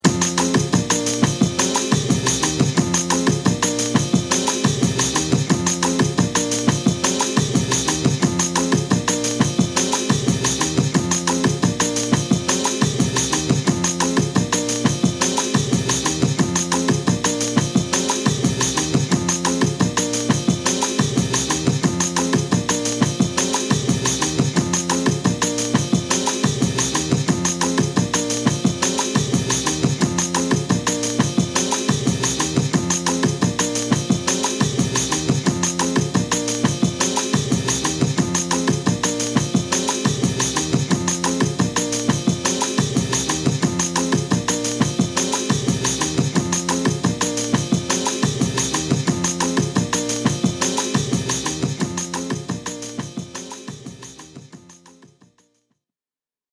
全曲3分ずつのジャズ・ファンク～ブラジリアン・テイストのエディット・ループを収録。